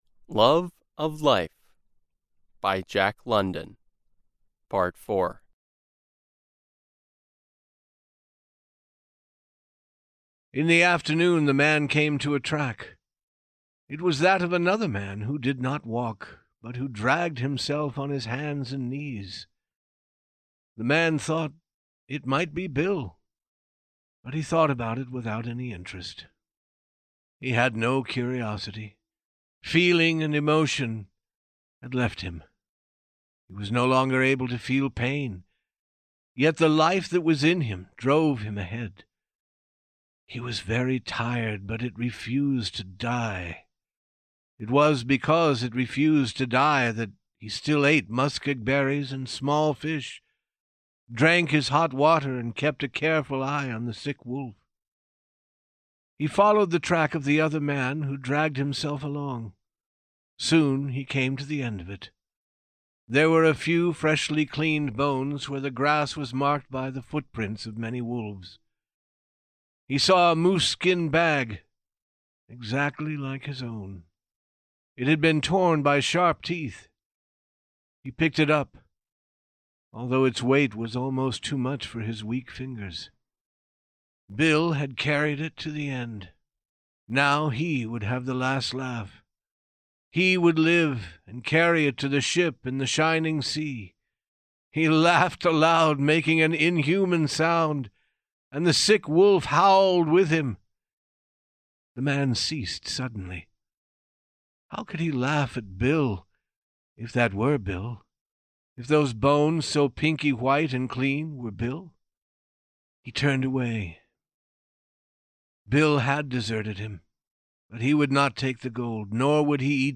We present the last of four parts of the short story "Love of Life," by Jack London. The story was originally adapted and recorded by the U.S. Department of State.